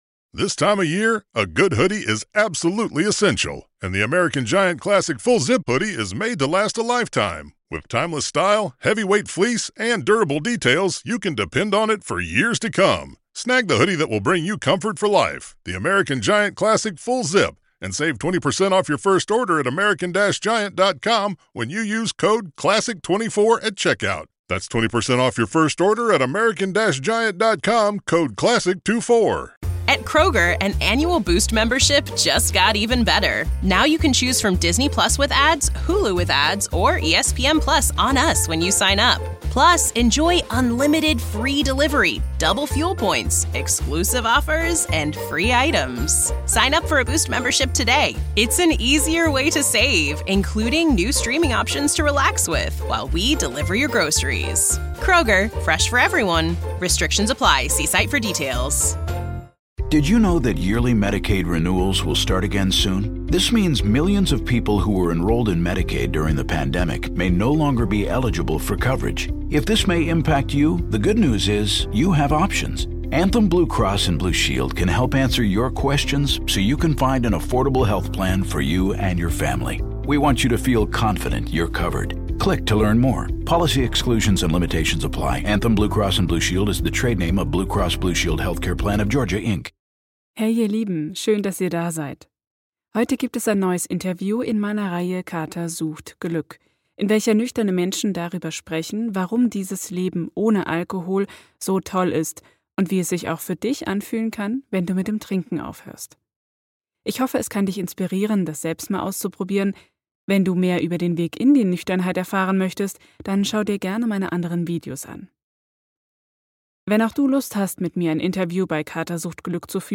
Ich interviewe nüchtern lebende Menschen darüber, wie toll es ist nüchtern zu leben - für dich zu Inspiration, das auch mal auszuprobieren oder um dran zu bleiben - in meiner Reihe Kater. Sucht. Glück.